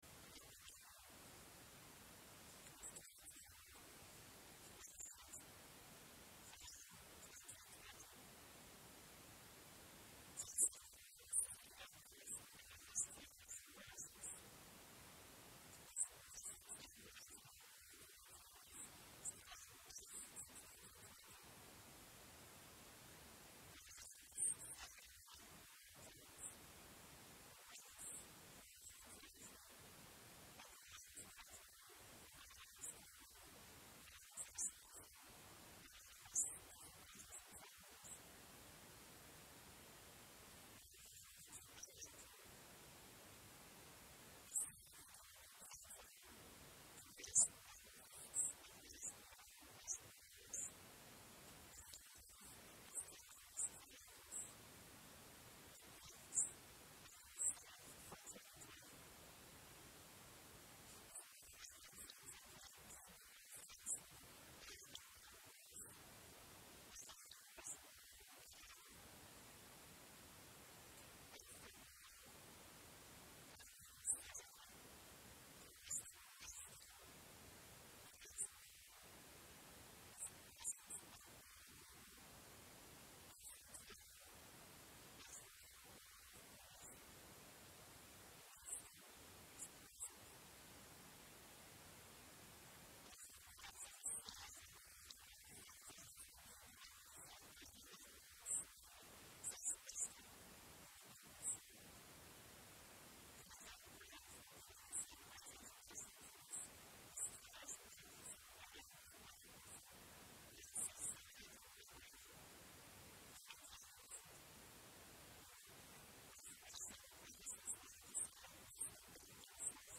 A sermon from January 3, 2021 (Christmas 2B)